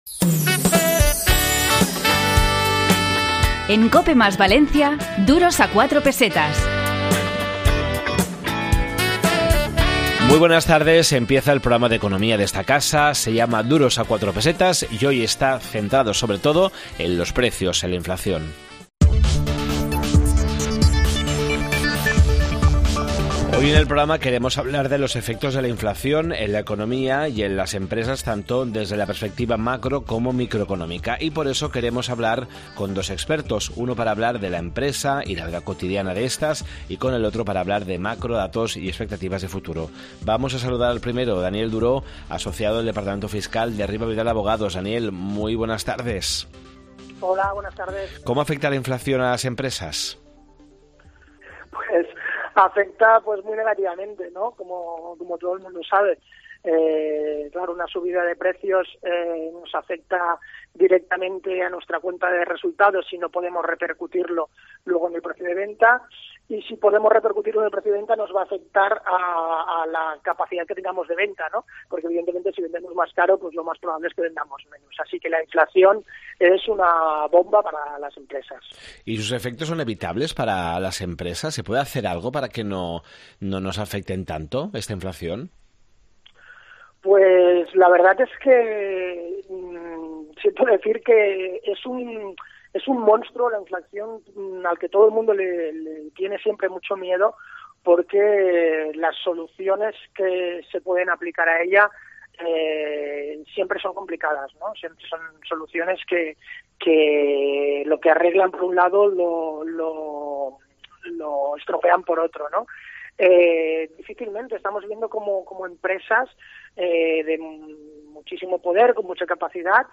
A lo largo de la entrevista, hemos aprendido si los efectos de la inflación son evitables por parte de las compañías en modo alguno y si existe algún tipo de planificación u acción posible a nivel fiscal.